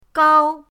gao1.mp3